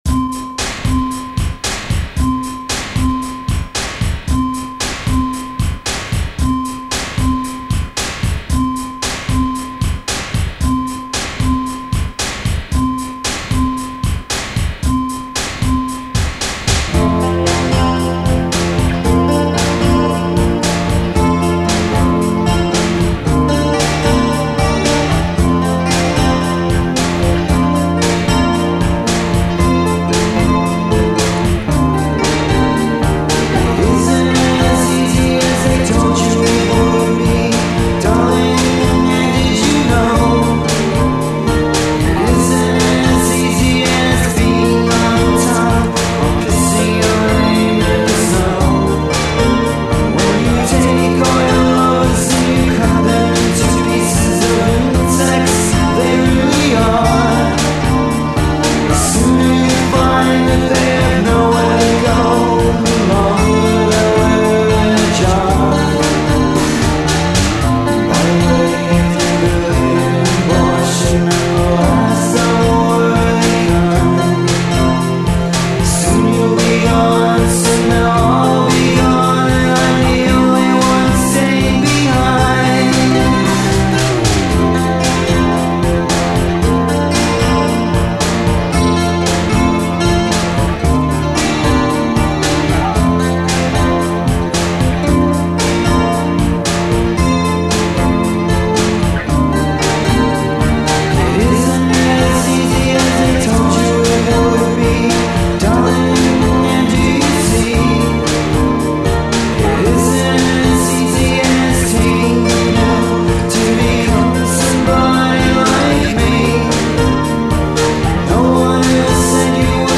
industrial-sounding material
guitars, basses, keyboards, programming, vocals